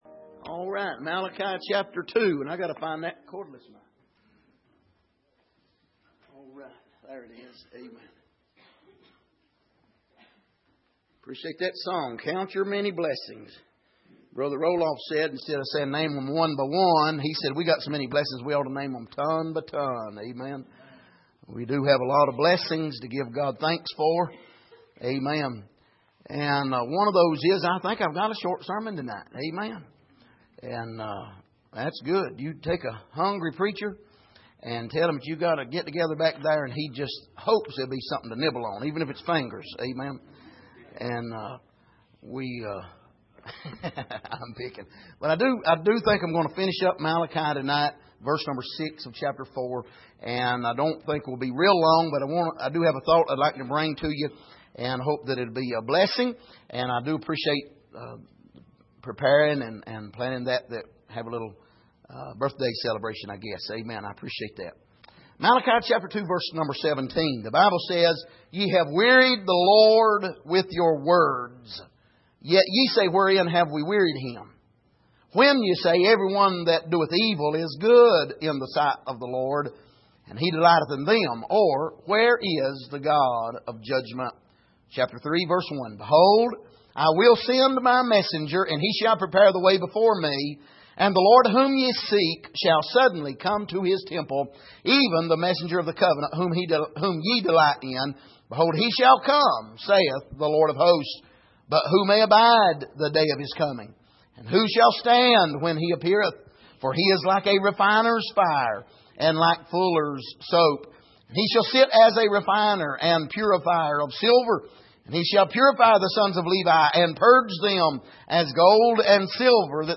A Study in Malachi Passage: Malachi 2:17 Service: Sunday Evening Where Is The God of Judgment?